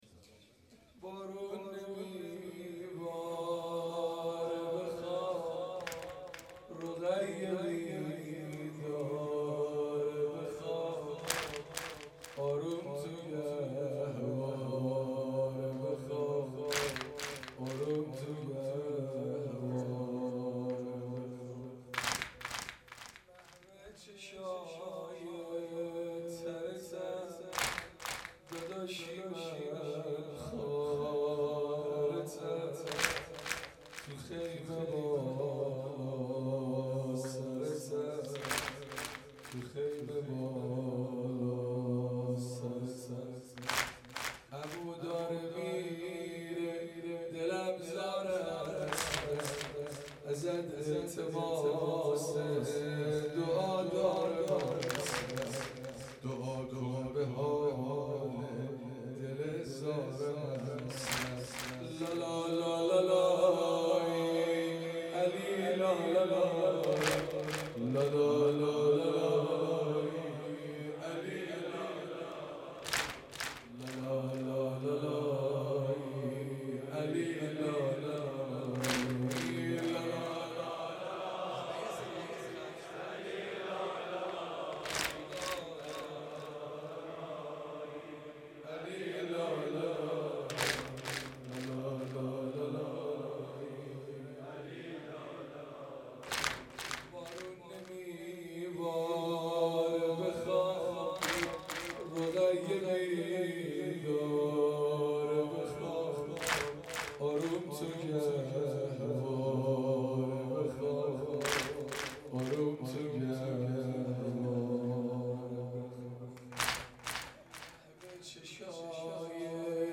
مناسبت : دهه دوم محرم